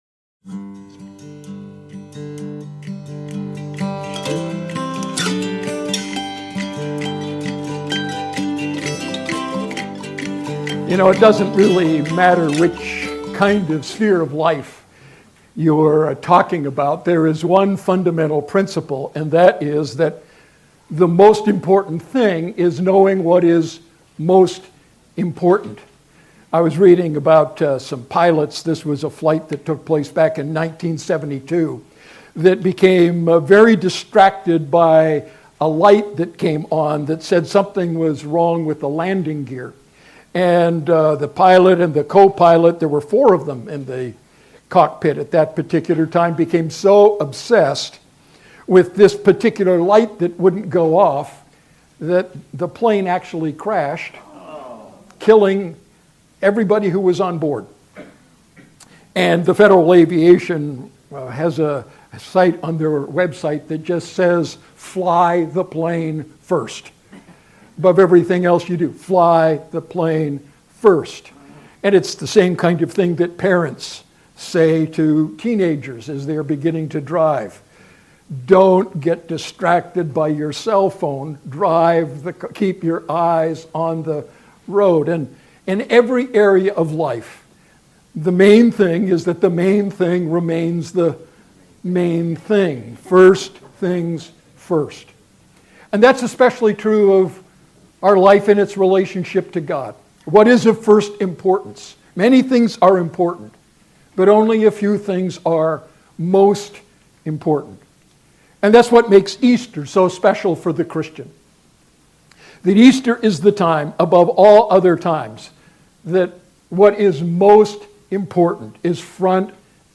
Sermon Archive, Redeemer Fellowship
Easter-Service-_-3-27-16-_-Redeemer-Loma-Linda.mp3